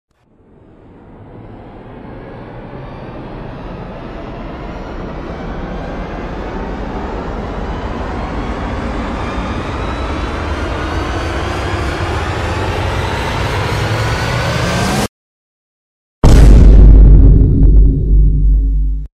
Звуки boom
Нарастающее напряжение и приближающийся бум